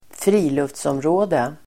Uttal: [²fr'i:luftsåmrå:de]